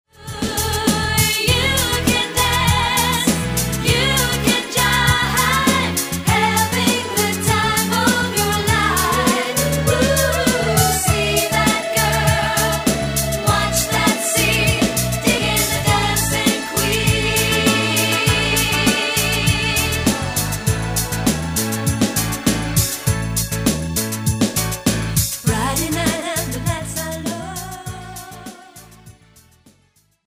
Swedish Pop